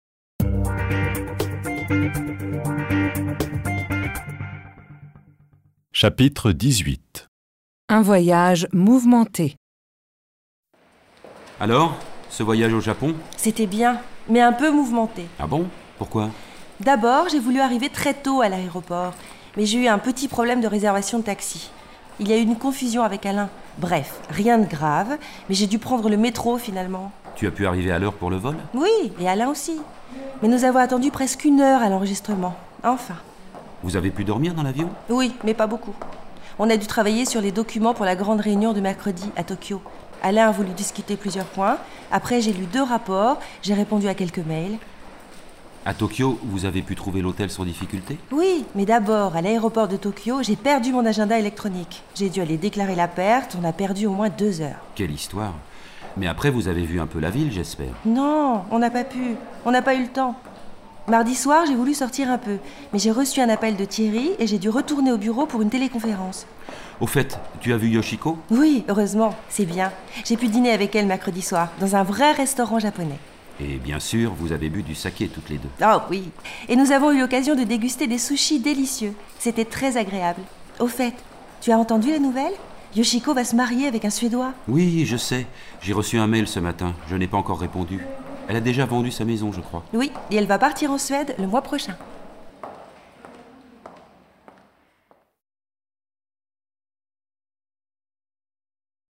dialogue10_voyage_mouvemente.mp3